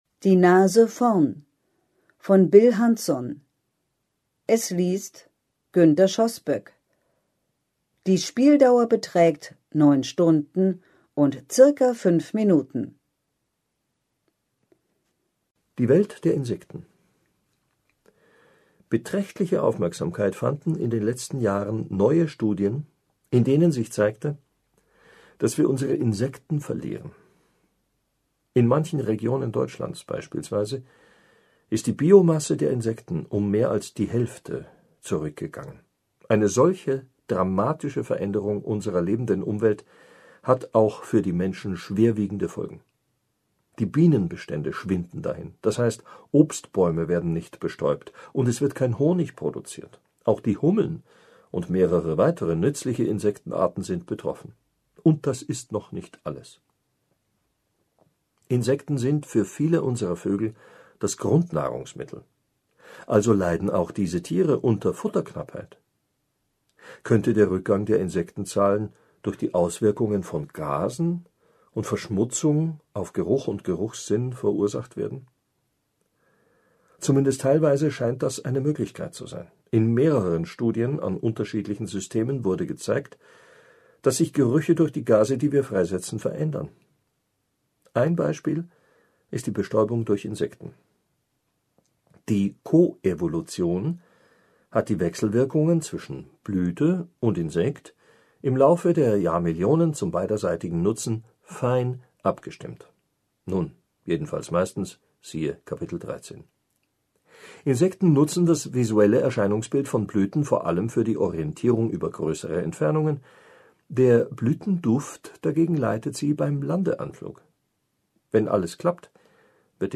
liest diesen höchst interessanten Blick auf die Welt der Gerüche: